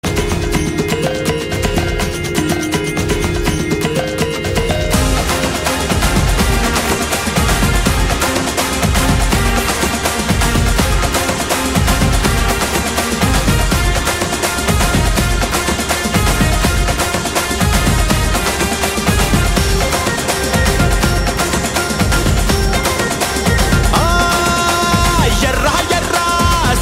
mass ringtone download